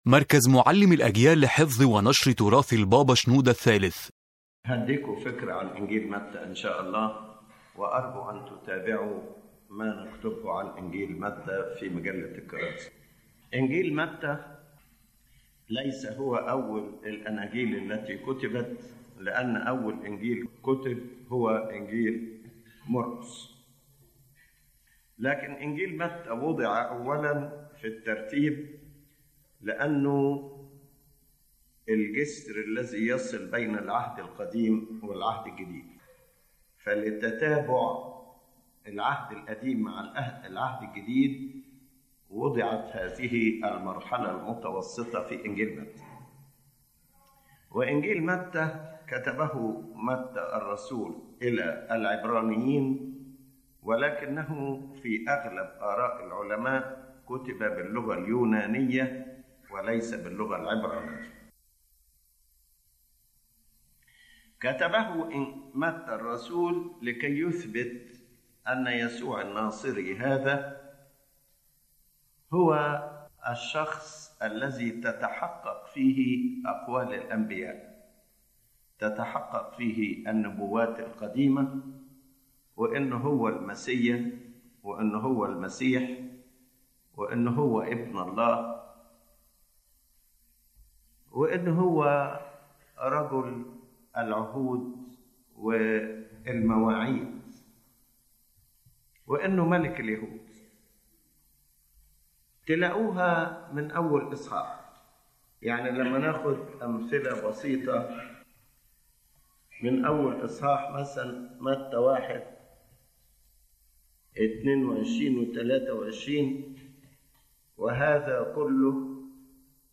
This lecture explains that the Gospel of Matthew was primarily written for the Jews to prove that Jesus Christ is the fulfillment of Old Testament prophecies, and that He is the awaited Messiah, the King of the Jews, and the Son of God, emphasizing that His kingdom is not earthly but a heavenly spiritual kingdom.